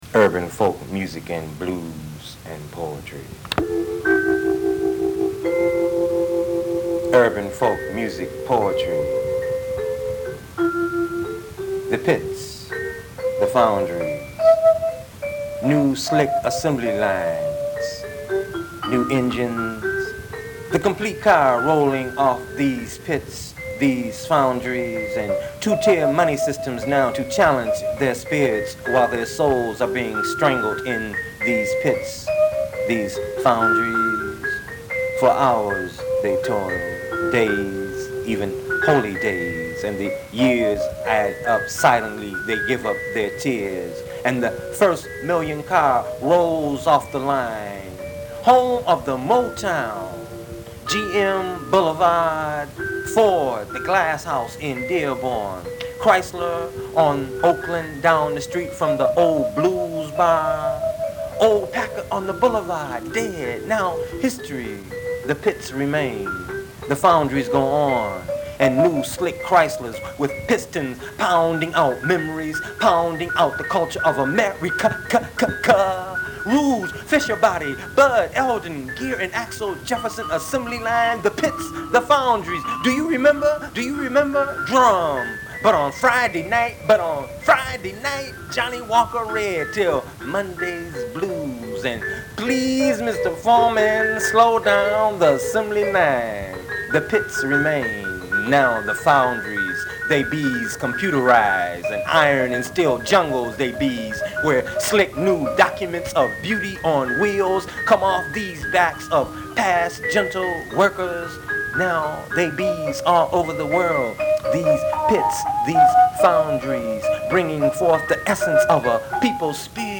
piano and vibes